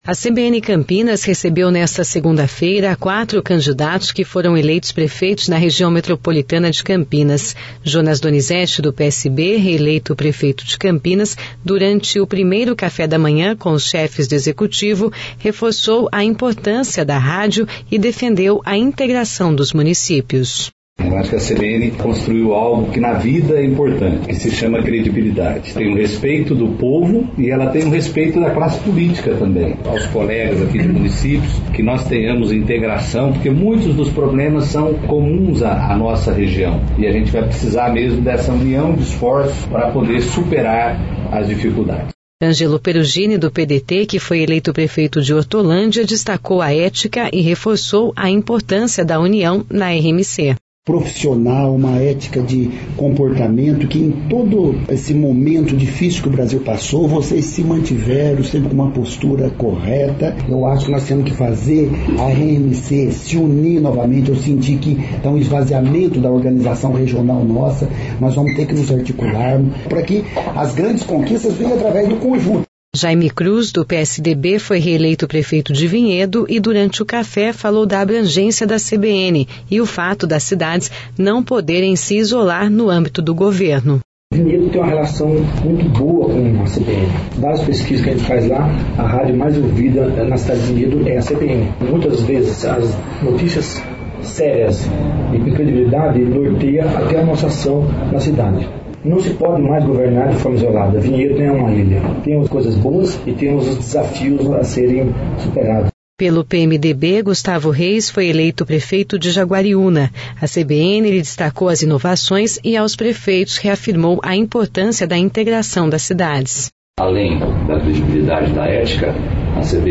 A CBN Campinas recebeu nesta segunda-feira (03/10), quatro candidatos que foram eleitos prefeitos na Região Metropolitana de Campinas.
Jonas Donizette do PSB, reeleito prefeito de Campinas, durante o 1° Café da Manhã com os chefes do executivo reforçou a importância da CBN e defendeu a integração dos municípios.